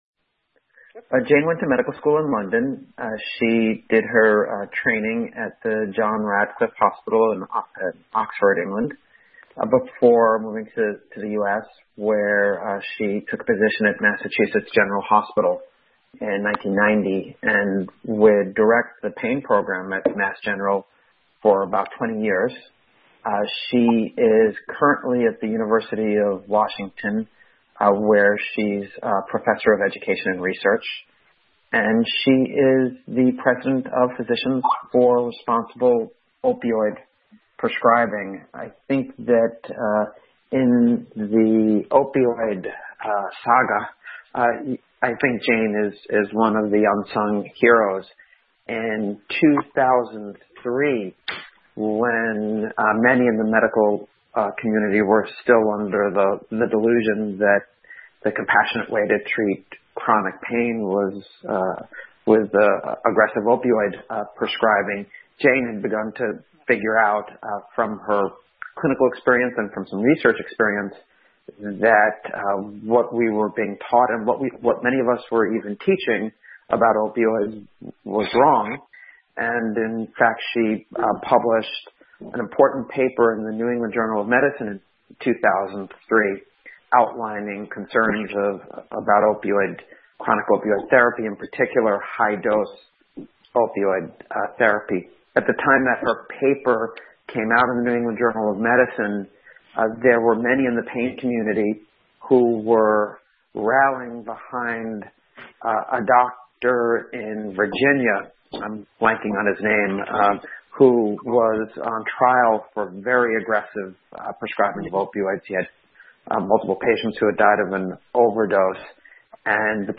NPA FDA Task Force Webinar – Dec 2015 – National Physicians Alliance